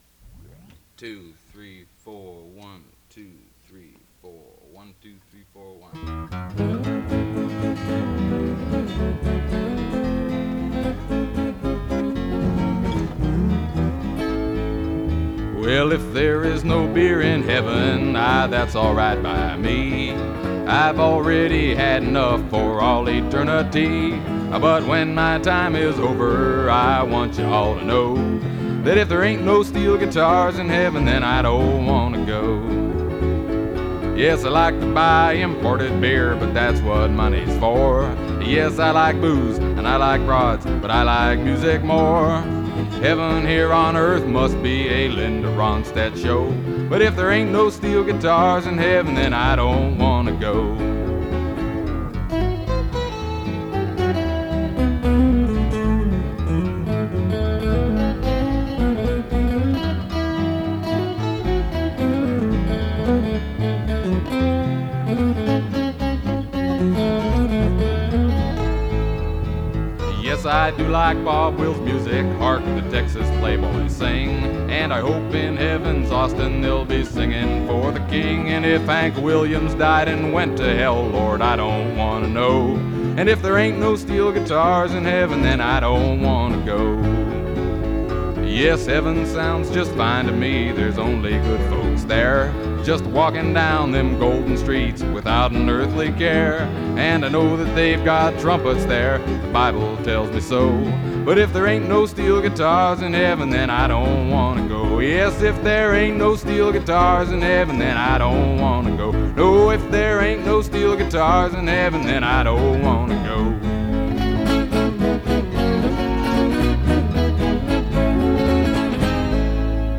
from cassette tape